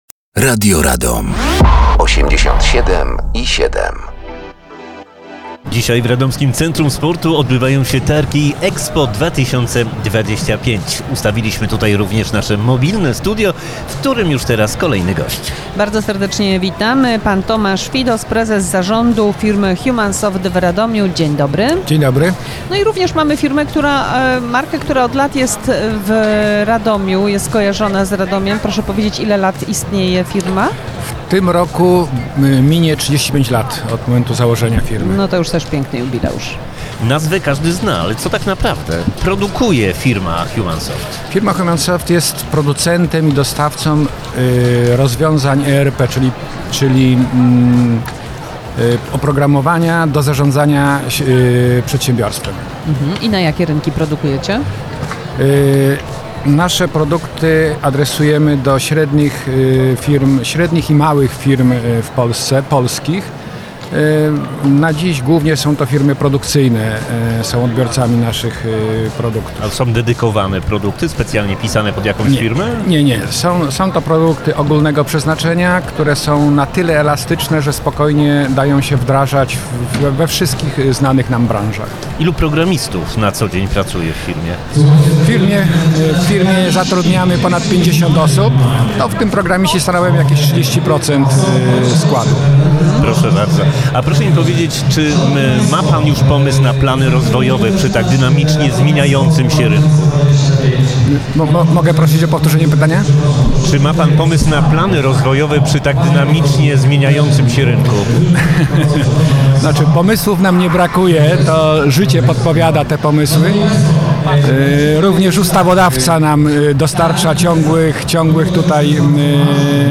Mobilne Studio
Trwają Targi EXPO 2025.